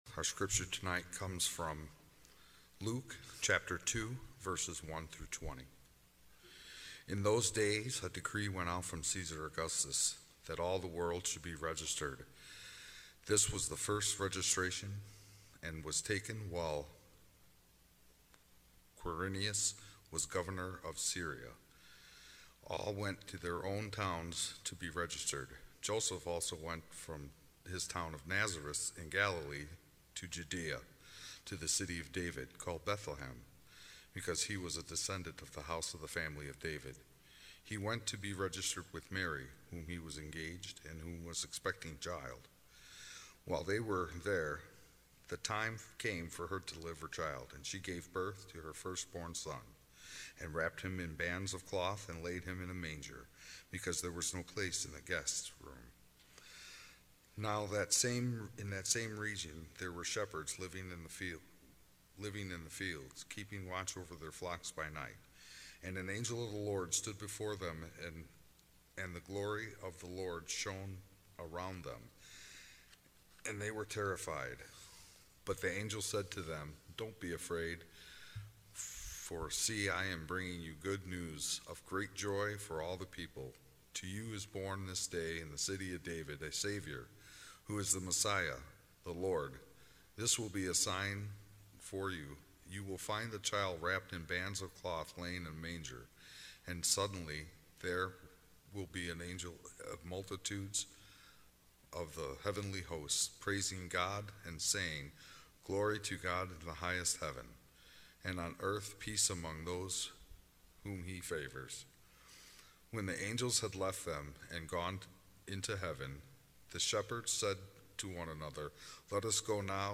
Sermons | Faith United Methodist Church of Orland Park